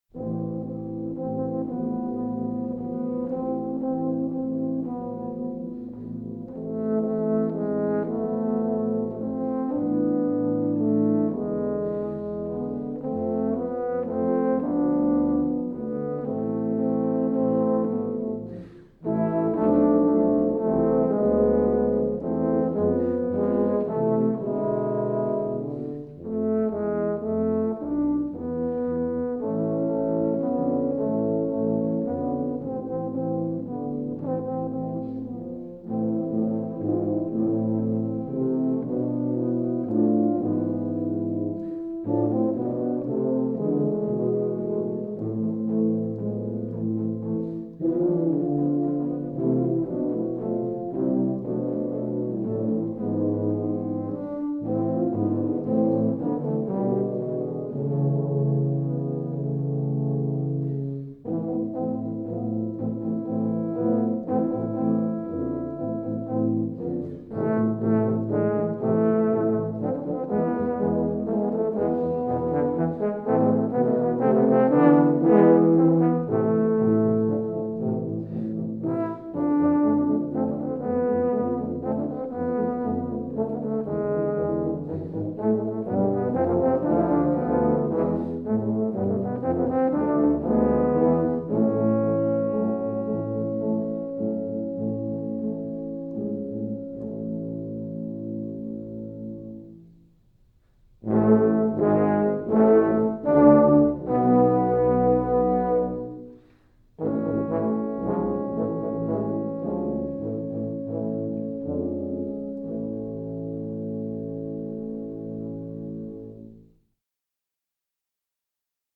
For Tuba Quartet (EETT), Composed by Traditional.